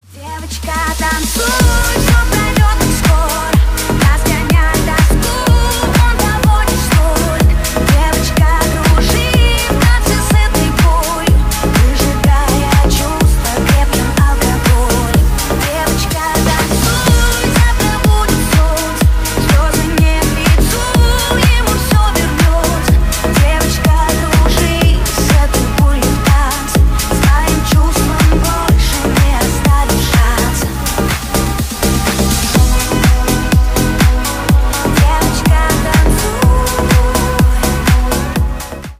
бесплатный рингтон в виде самого яркого фрагмента из песни
Ремикс # Танцевальные
клубные